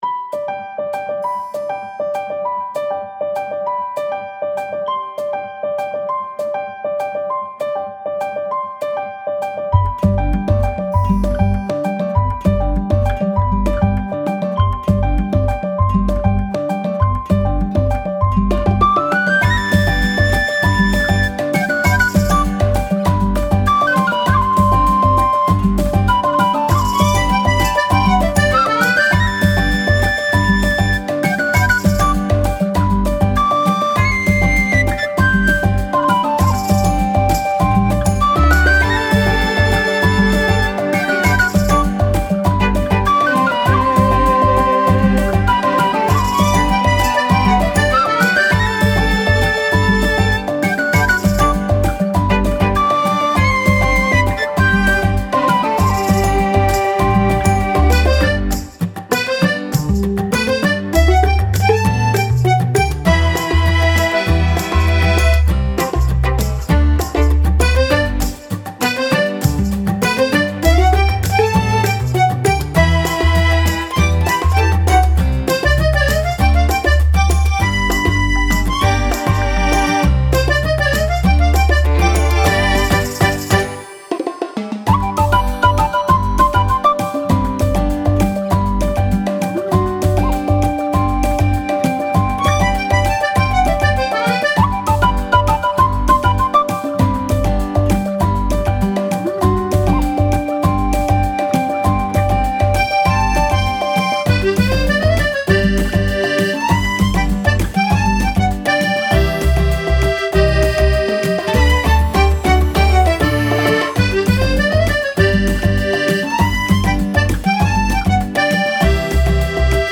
ピアノ、アコースティックギター、アコーディオン、ドヴォヤチカという笛が使用されている爽やかな楽曲です。
BPM 99
爽快 アコギ バイオリン ヴィオラ
ポップ ベース ハッピー アコースティックギター 明るい ワクワク